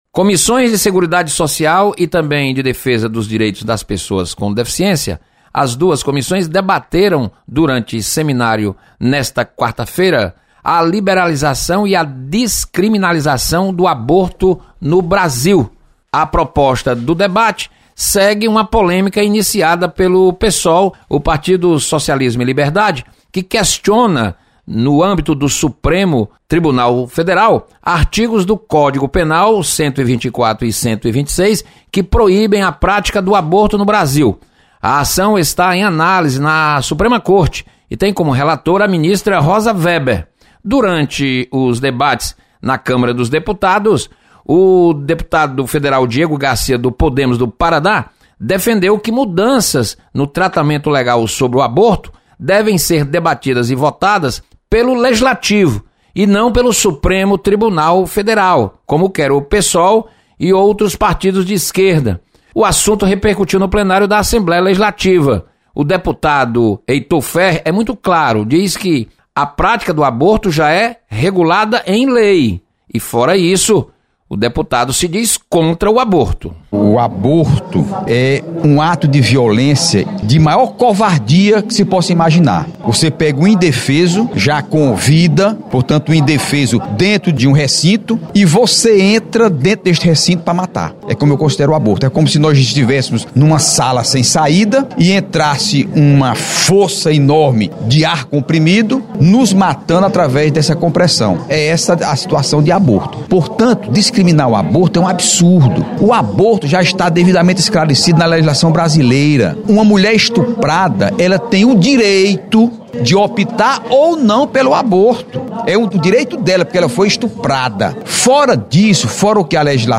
Debate sobre mudanças na Lei do Aborto repercute na Assembleia Legislativa. Repórter